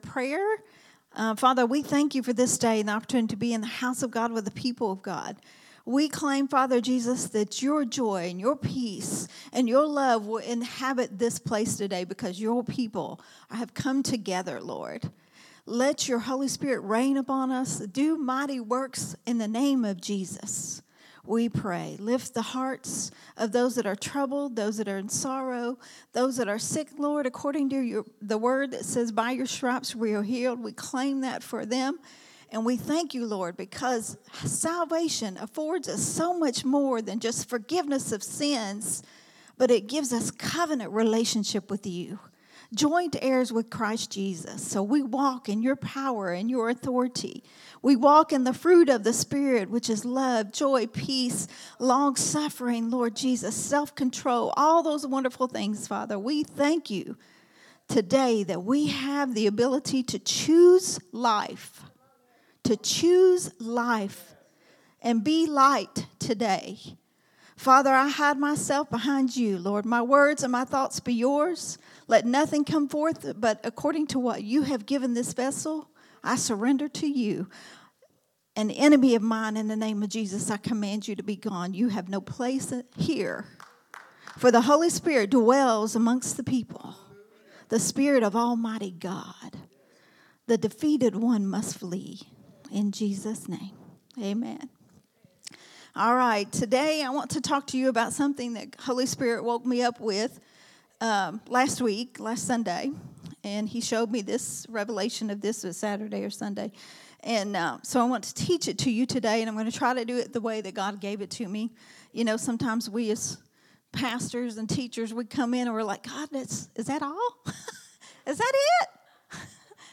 a Sunday Morning Risen Life teaching